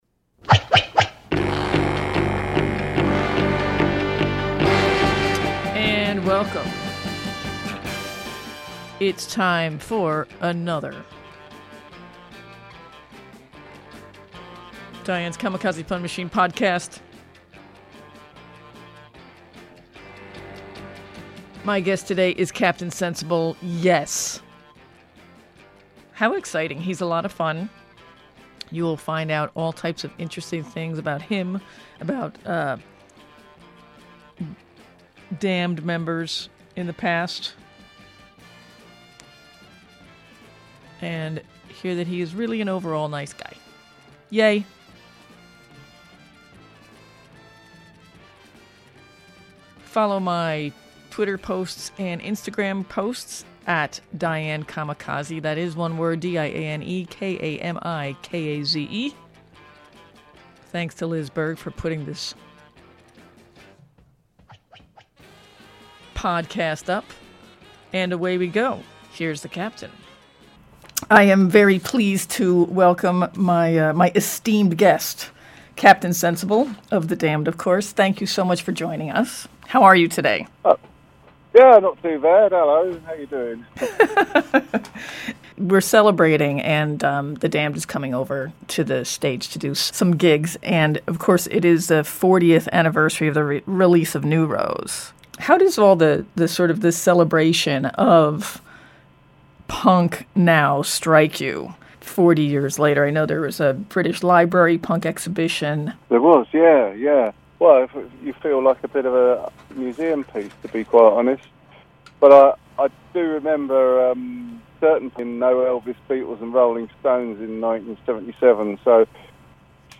Podcast #48: Interview with The Damned's Captain Sensible from Oct 26, 2016